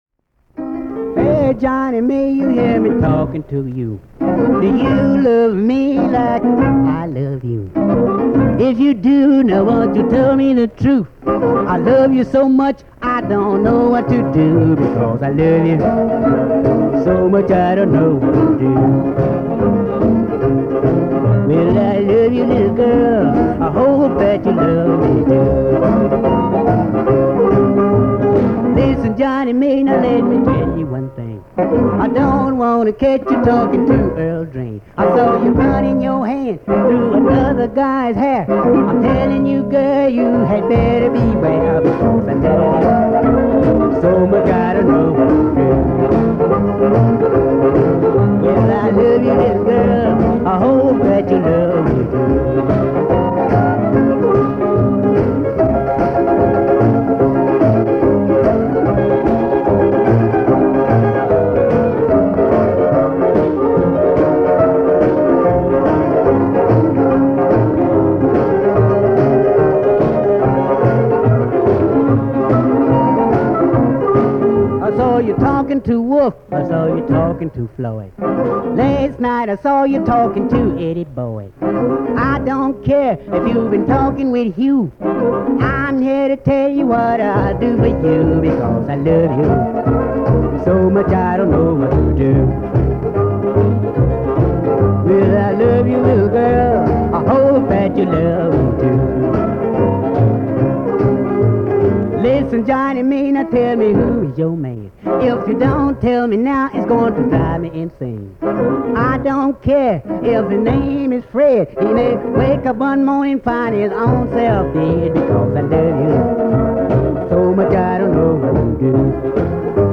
Chicago Blues
Their blues sound is typical of that time and place.
Recorded in Nashville, TN, 1955.
vocal and piano
harmonica
guitar